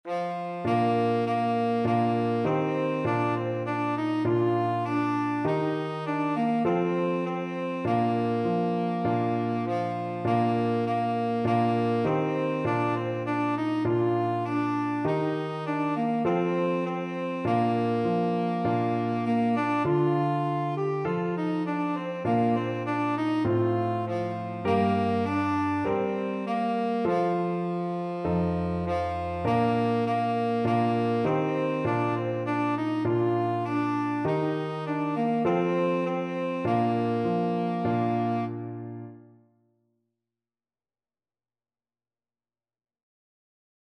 Christmas
4/4 (View more 4/4 Music)
F4-G5